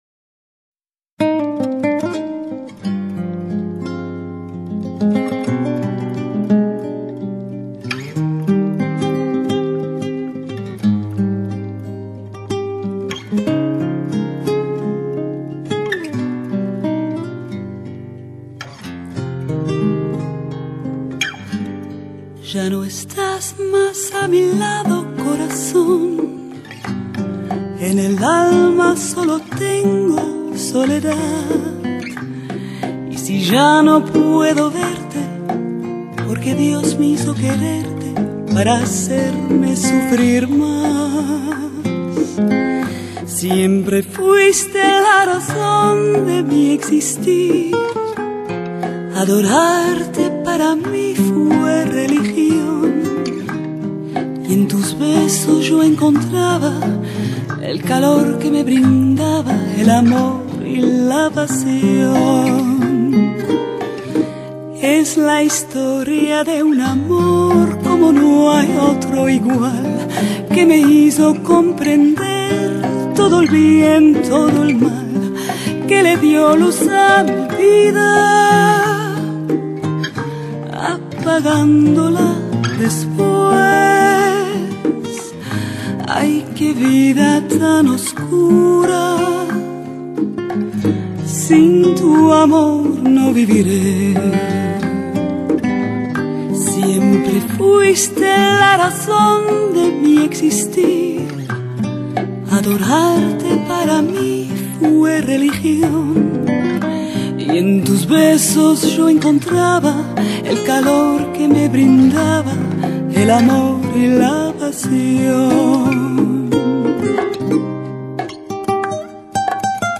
她以其独特的带磁性的歌喉吸引了全球无数听众